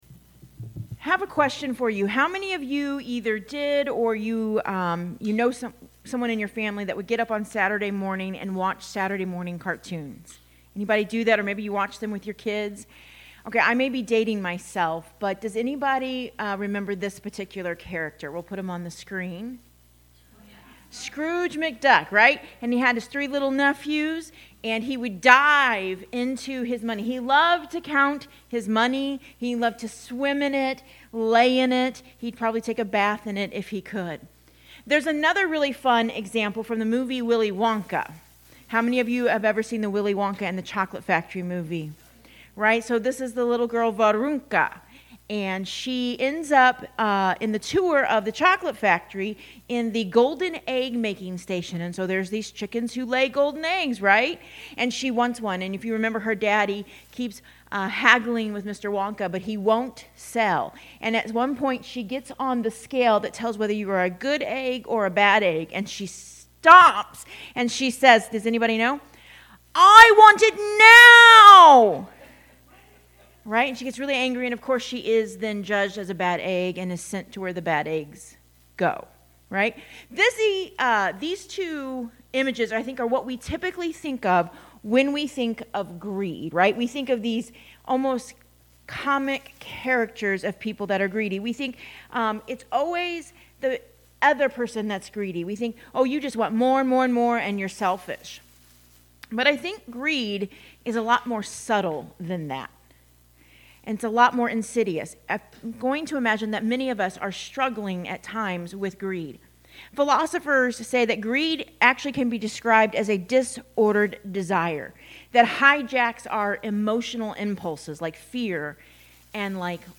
Sermons | Compassion Church